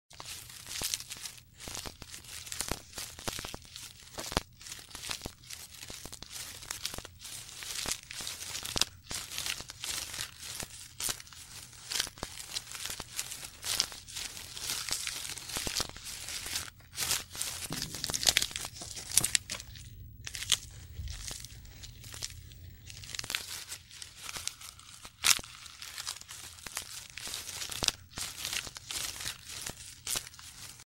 Звуки жирафа